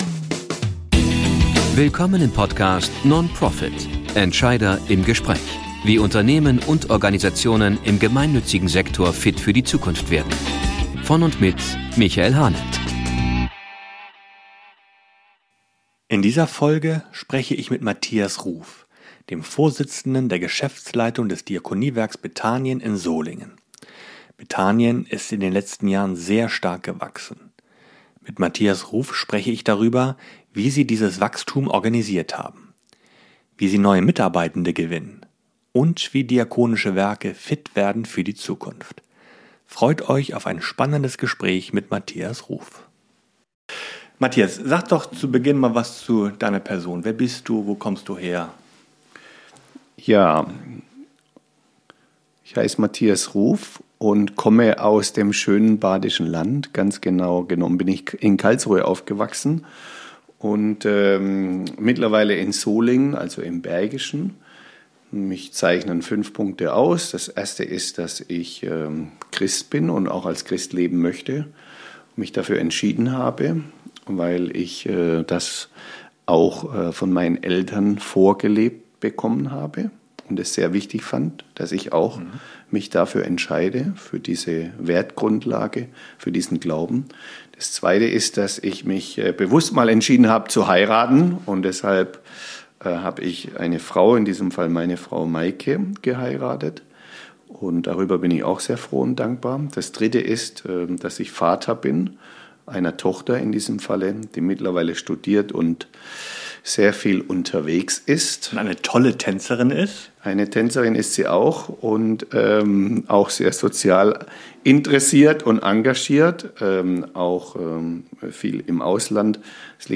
im Gespräch ~ Nonprofit